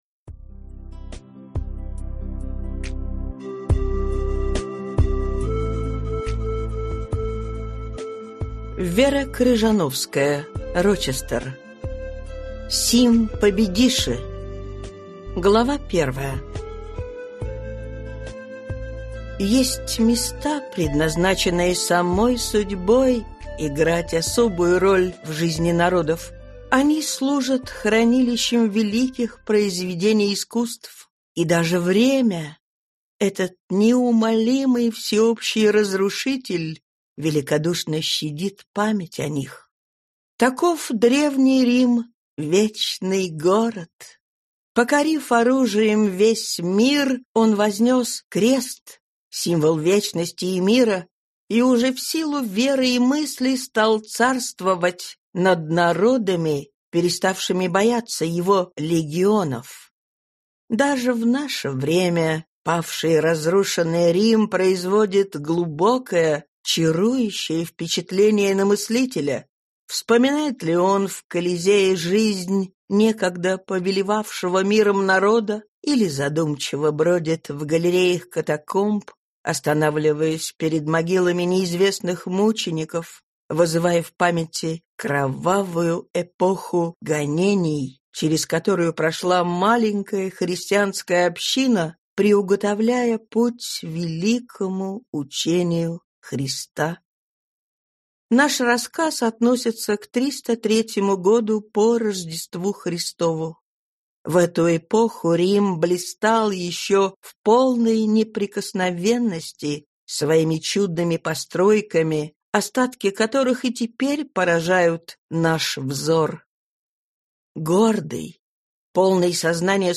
Аудиокнига Сим победиши | Библиотека аудиокниг
Aудиокнига Сим победиши Автор Вера Ивановна Крыжановская-Рочестер